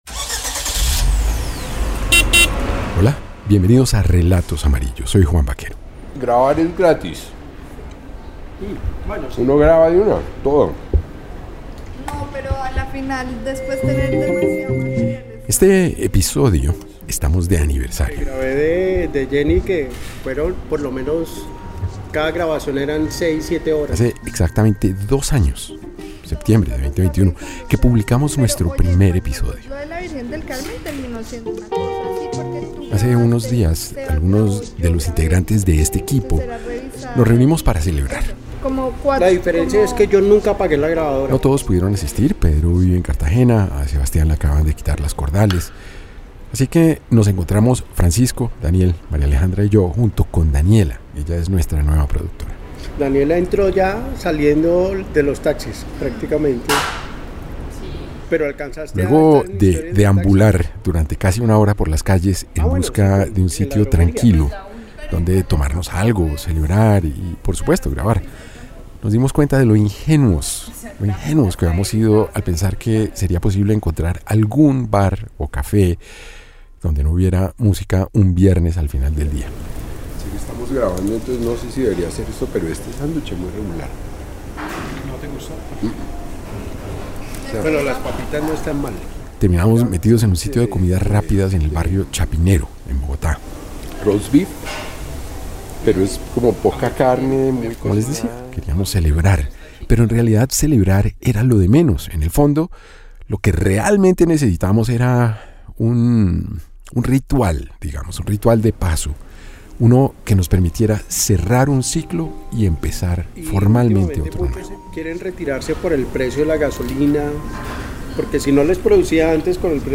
..Podcast narrativo.